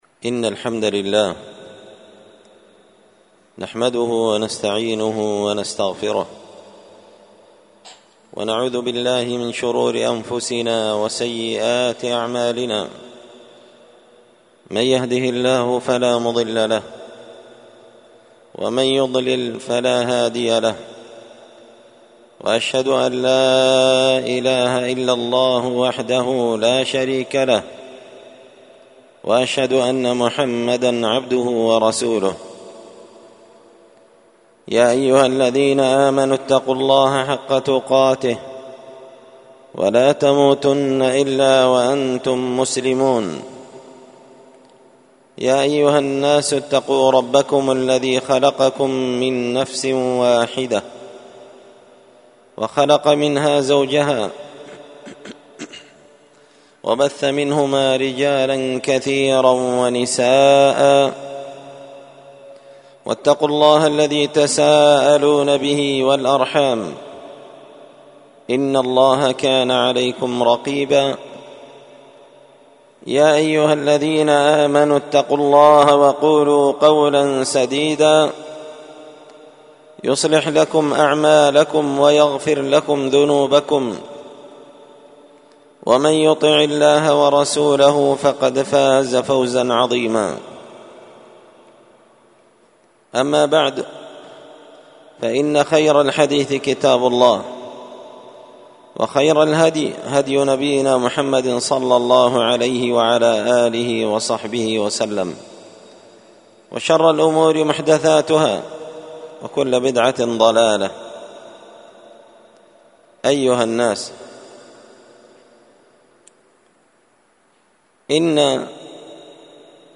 الجمعة 3 شوال 1445 هــــ | الخطب والمحاضرات والكلمات | شارك بتعليقك | 157 المشاهدات
ألقيت هذه الخطبة بدار الحديث السلفية بمسجد الفرقان قشن -المهرة-اليمن تحميل